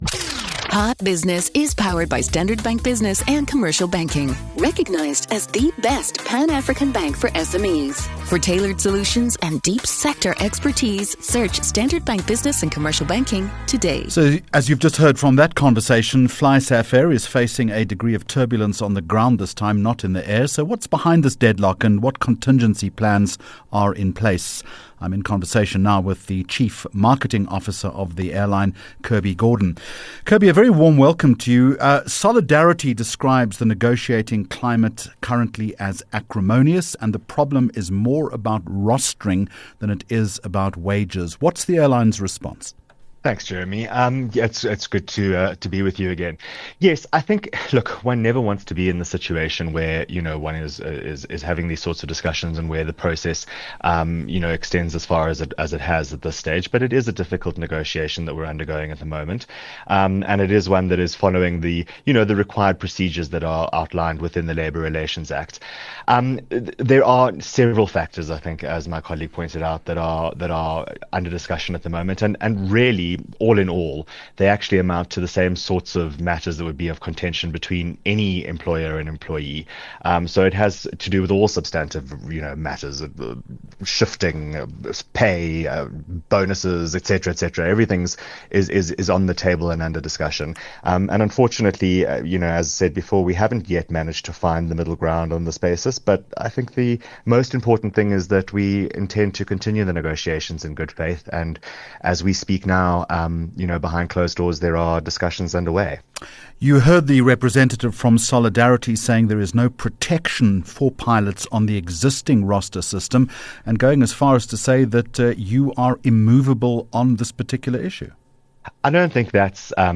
17 Jul Hot Business Interview